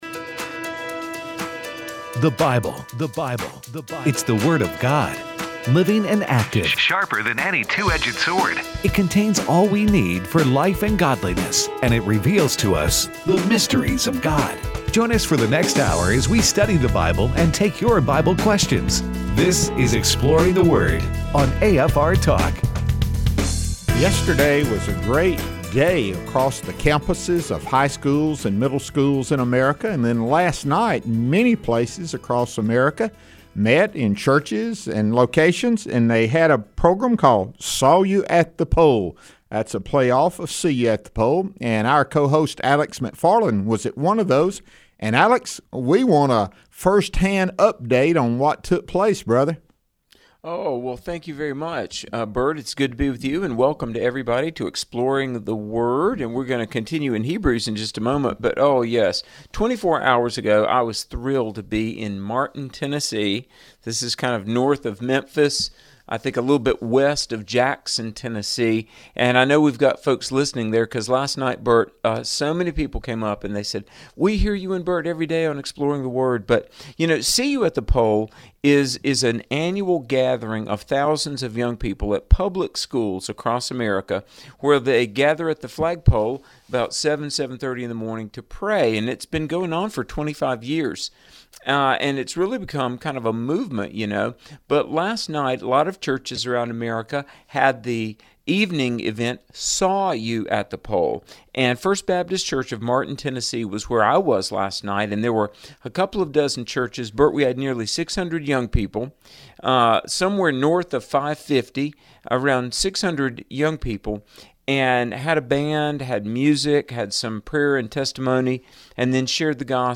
discuss Hebrews 4 and takes your questions in last segment of the show.